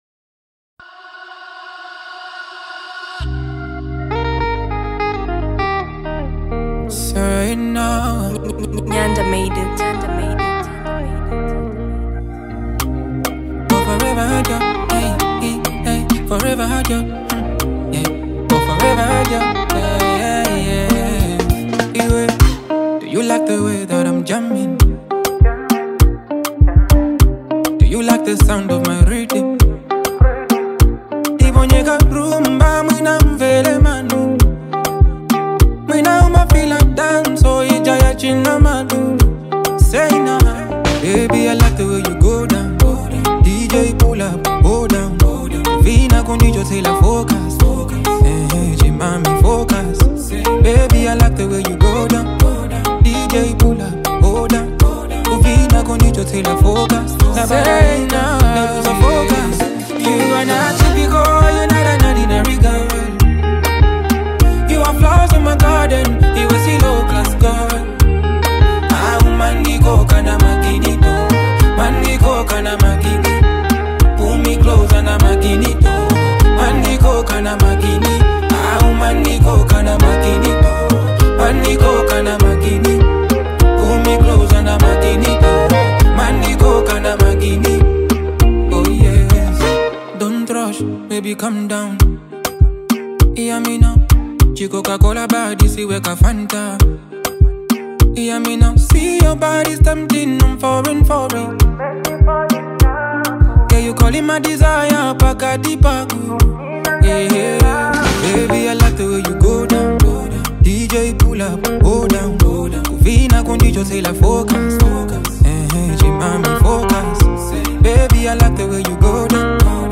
Universal Gospel